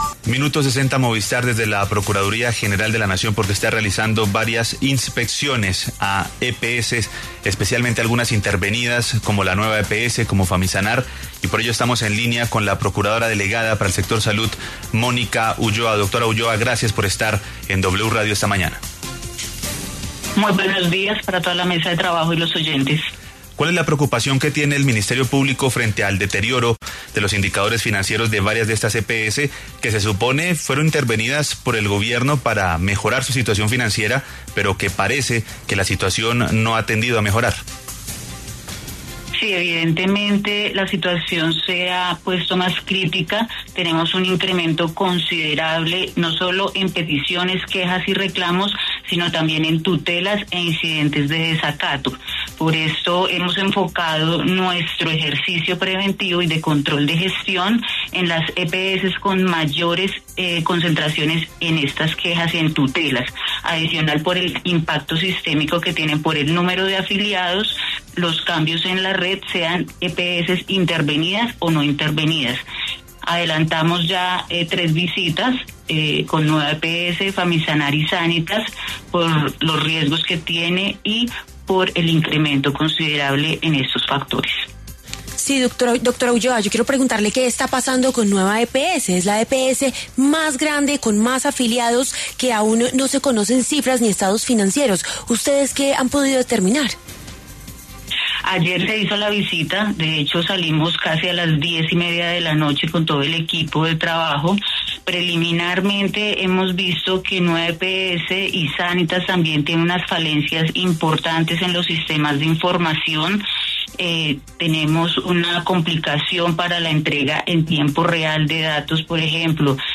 En entrevista con La W, la procuradora delegada para el sector Salud, Mónica Ulloa Ruiz, se refirió a las inspecciones que están realizando a varias EPS, algunas de ellas intervenidas por el gobierno, ante el deterioro de sus indicadores financieros.